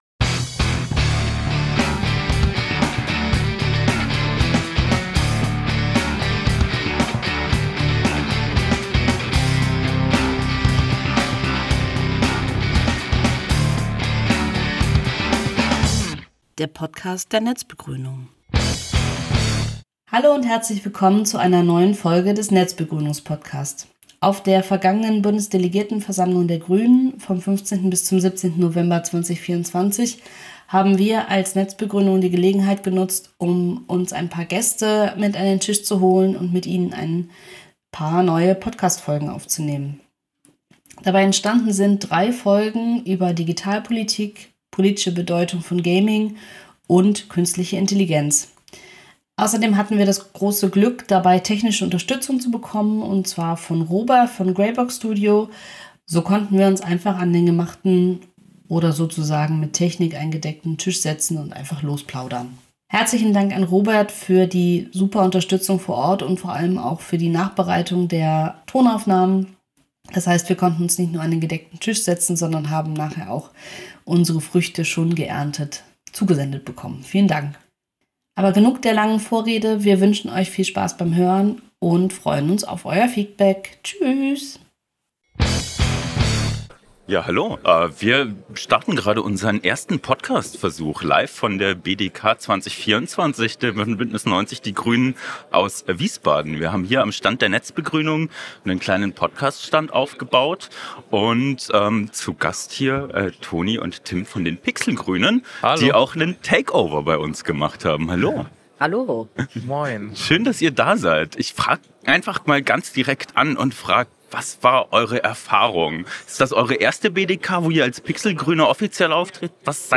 Auf der 50. Bundesdelegiertenkonferenz hat die netzbegrünung die Gelegenheit genutzt, drei Podcastfolgen mit Gästen aufzunehmen.